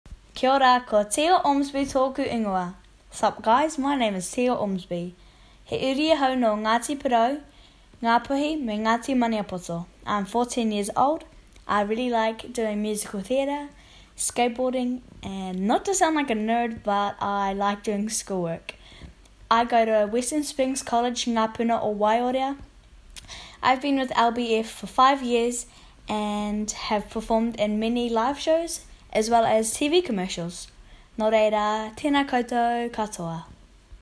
Demo
Child, Teenager
new zealand | natural
warm/friendly